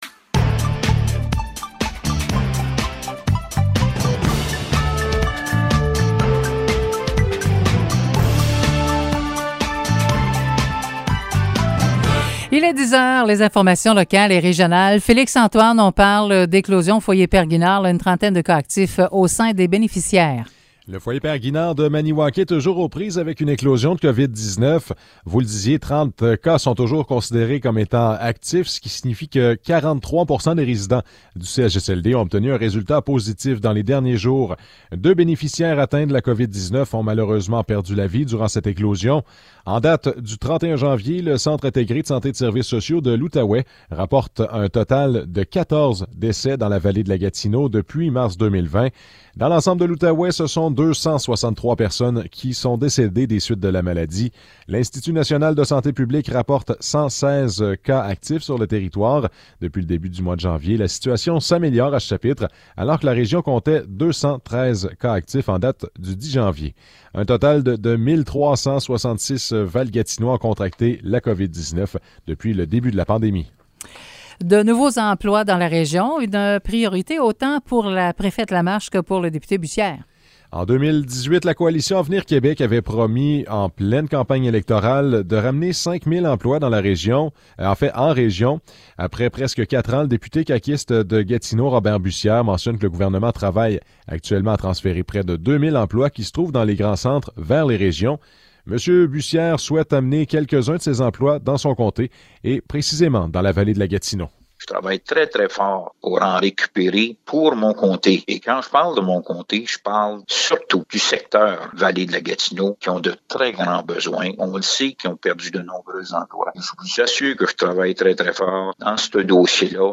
Nouvelles locales - 2 février 2022 - 10 h